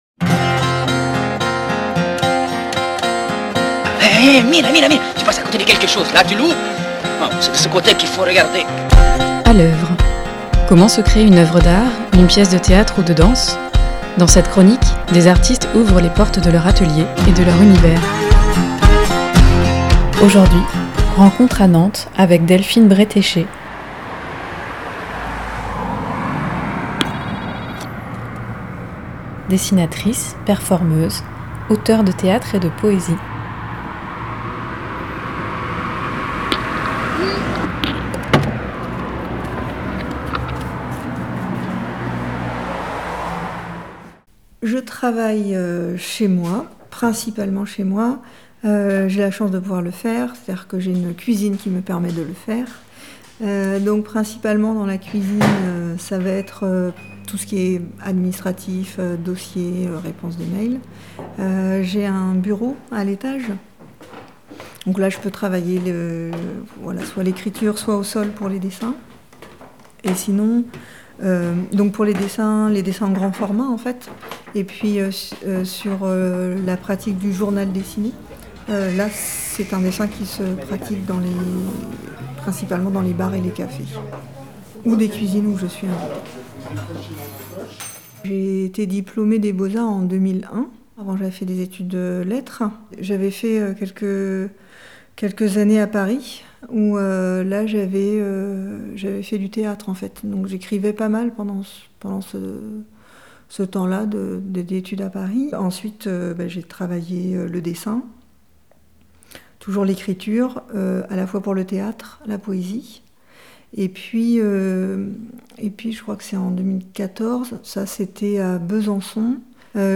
Comment se crée une œuvre d’art, une pièce de théâtre ou de danse ? Dans cette chronique, des artistes ouvrent les portes de leur atelier et de leur univers.